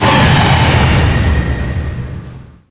.爆炸.ogg